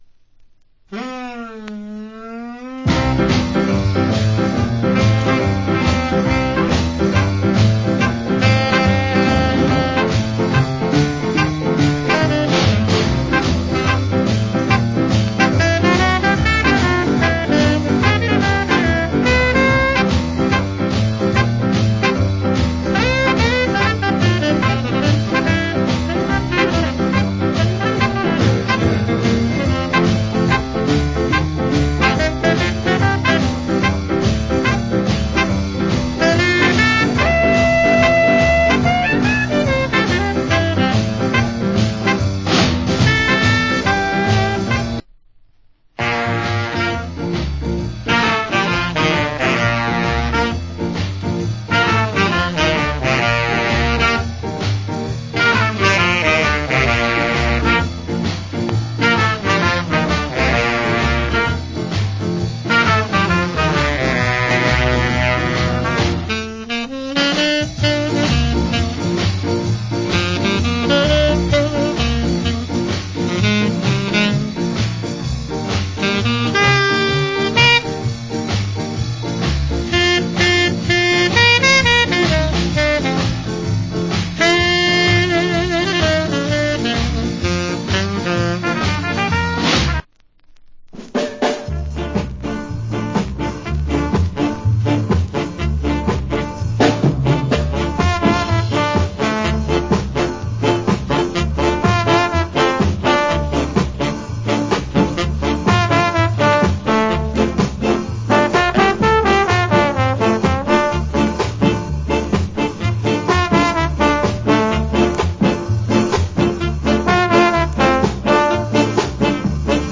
Nice Ska